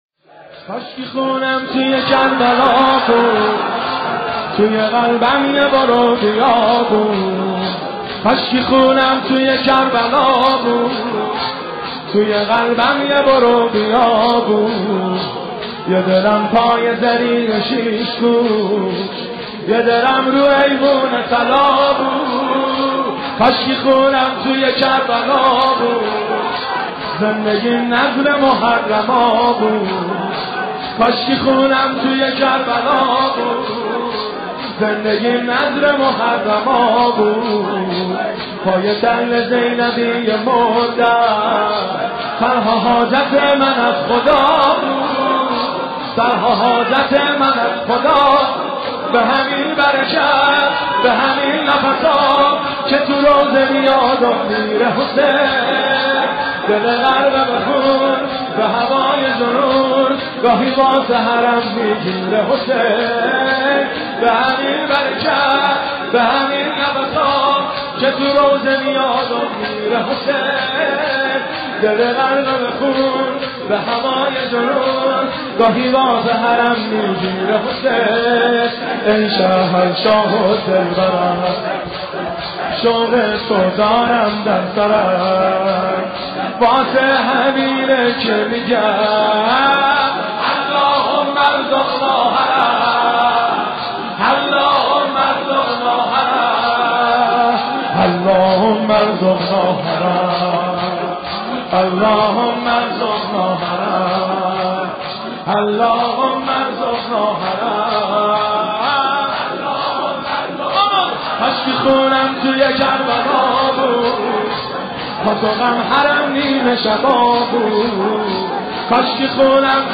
مداحی کاشکی خونم توی کربلا بود